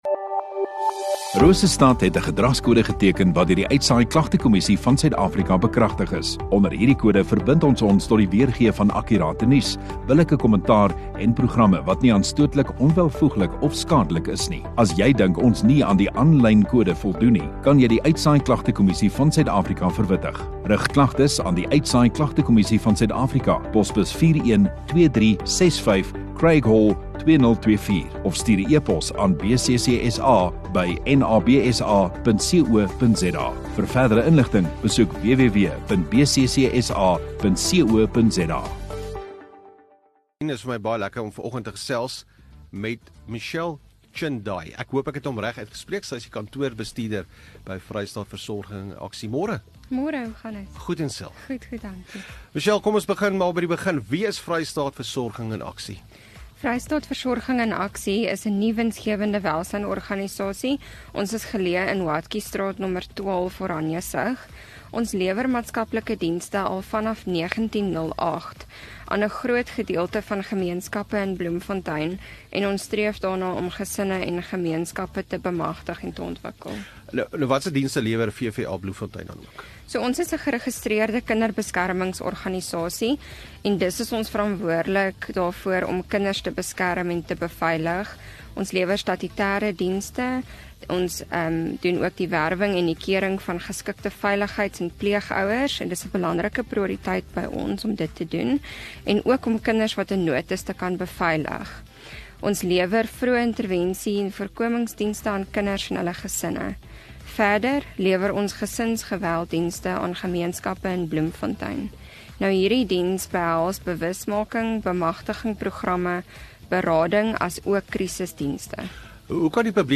Radio Rosestad View Promo Continue Radio Rosestad Install Gemeenskap Onderhoude 22 Jul Vrystaat Versorging in Aksie